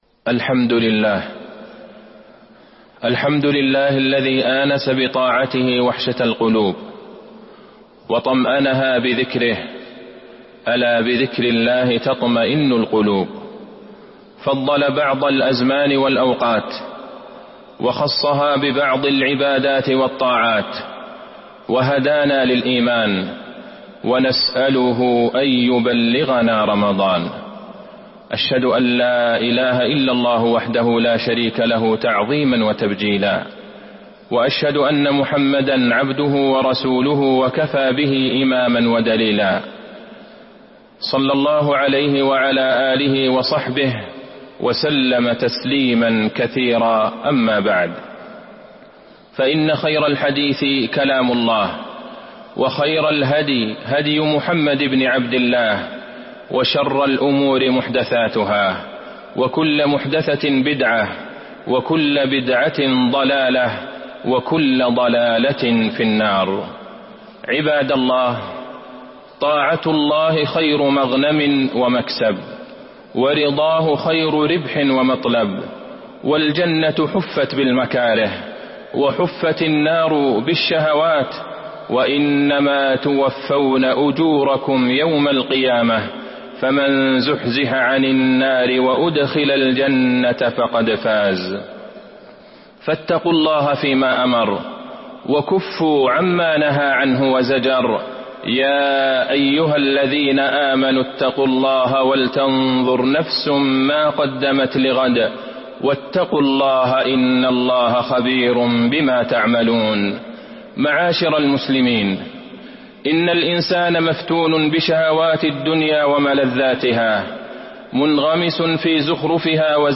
تاريخ النشر ٢٧ شعبان ١٤٤٢ هـ المكان: المسجد النبوي الشيخ: فضيلة الشيخ د. عبدالله بن عبدالرحمن البعيجان فضيلة الشيخ د. عبدالله بن عبدالرحمن البعيجان استقبال شهر رمضان